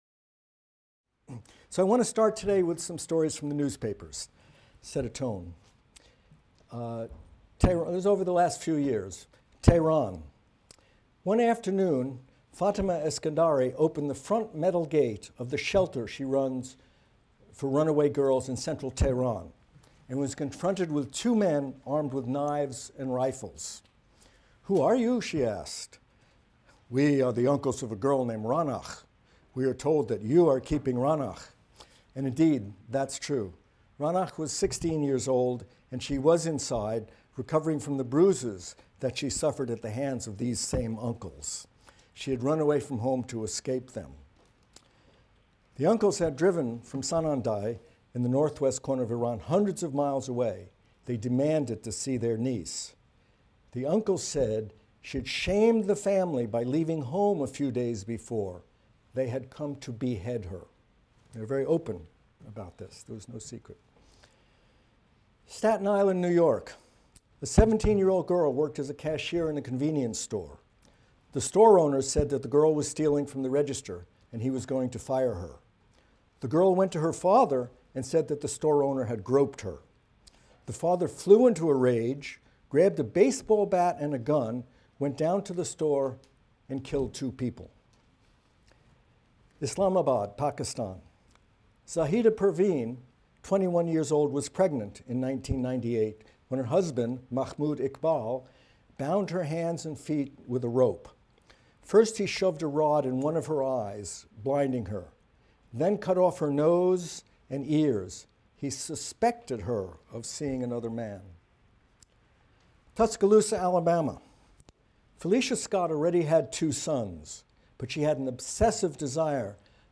MCDB 150 - Lecture 1 - Evolution of Sex and Reproductive Strategies | Open Yale Courses